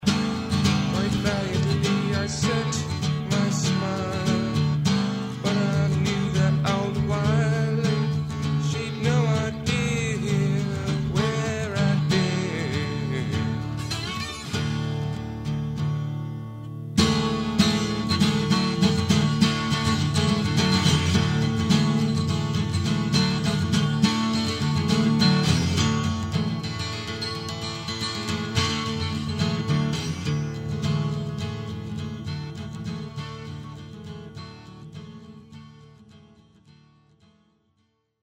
12-string guitar/slide, vocals